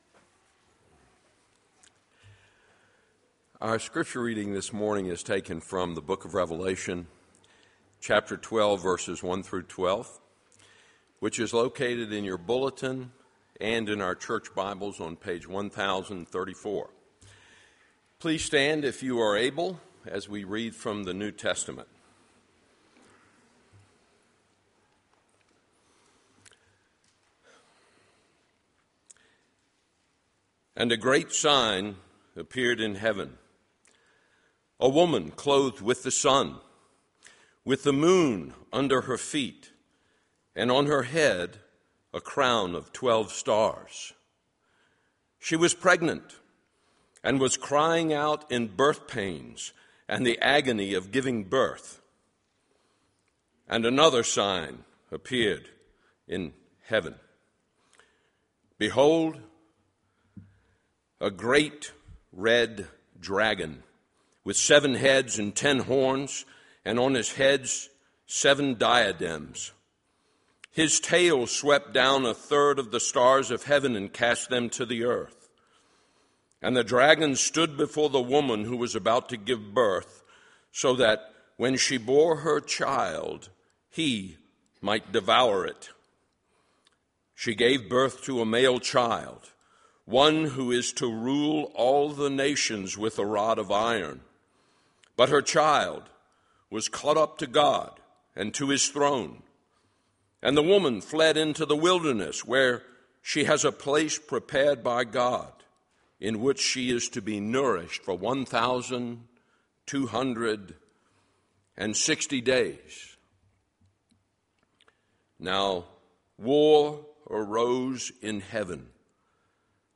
Sermon Revelation 12:1-12 (ESV)